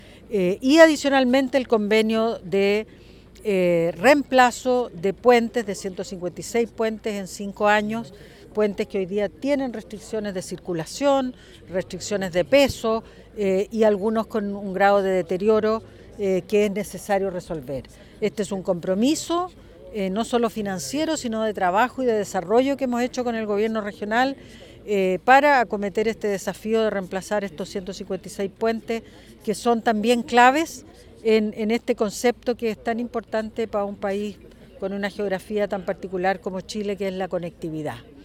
Del mismo modo, frente al convenio de puentes, la jefa de cartera de Obras Públicas indicó que existe un convenio de trabajo que es clave en la conectividad de los sectores rurales.